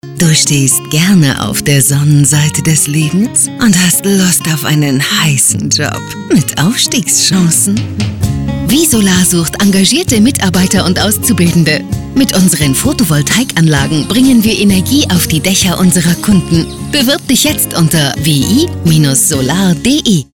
radiospot-recruiting-wi-solar-rockland-20-sek.mp3